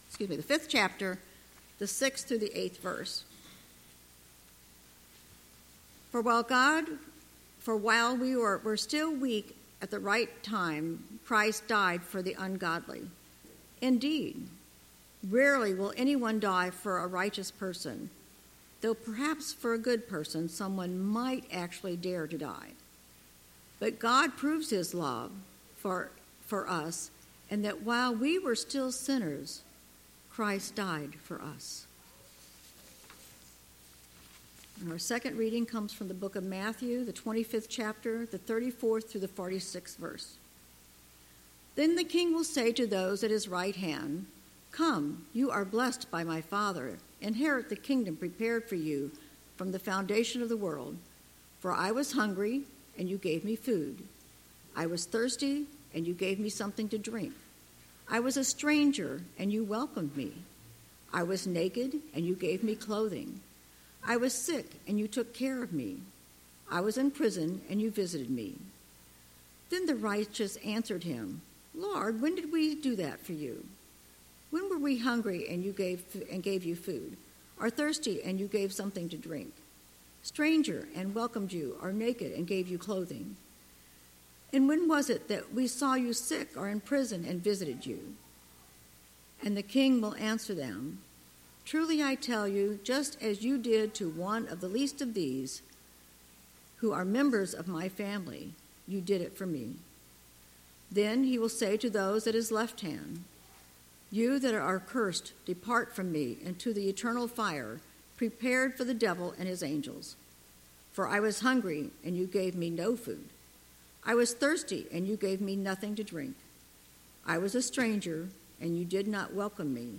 Passage: Romans 5: 6-8; Matthew 25:34-46 Service Type: Sunday Morning